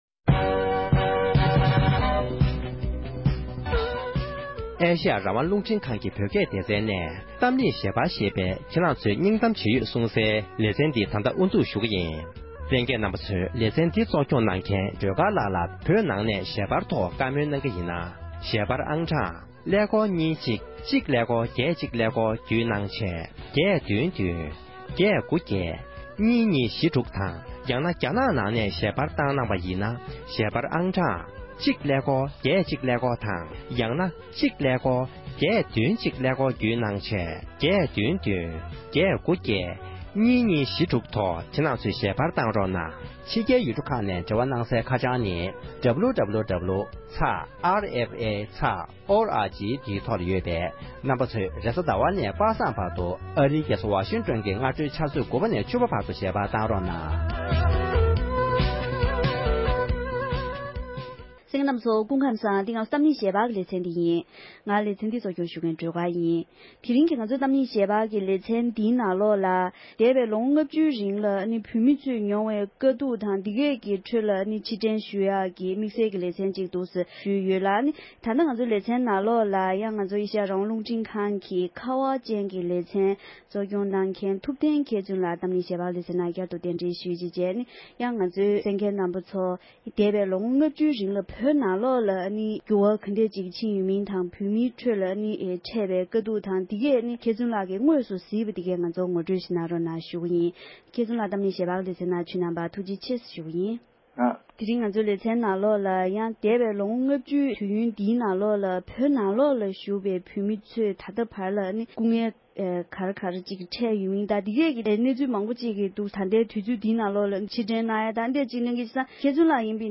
༄༅༎དེ་རིང་གི་གཏམ་གླེང་ཞལ་པར་གྱི་ལེ་ཚན་ནང་དུ་འདས་པའོ་ལོ་ངོ་ལྔ་བཅུའི་རིང་བོད་ནང་གི་བོད་མི་ཚོས་རྒྱ་ནག་གཞུང་གི་སྲིད་ཇུས་འདྲ་མིན་འོག་དཀའ་སྡུག་ཚད་མེད་མྱོང་དགོས་བྱུང་ཡོད་པའི་སྐོར་ལ་ཕྱིར་དྲན་ཞུས་པའི་ལེ་ཚན་དང་པོར་གསན་རོགས༎